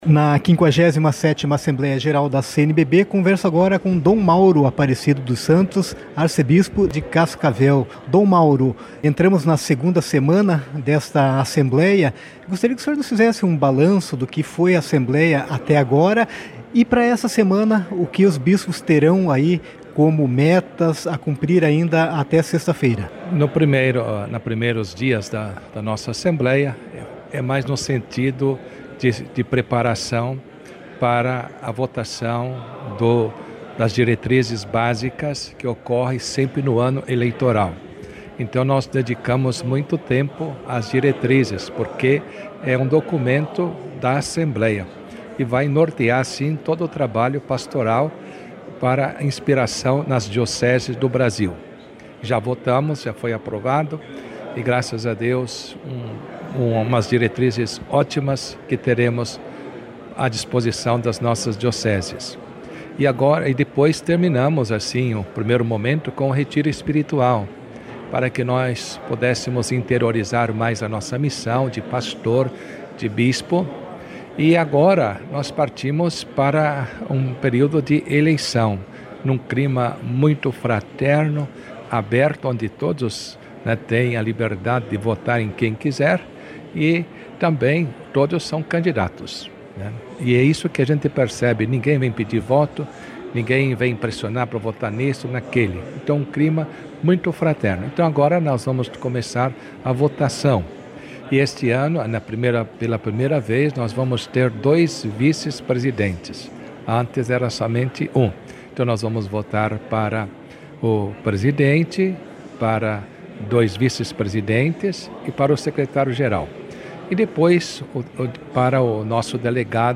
Entrevista-com-Dom-Mauro.mp3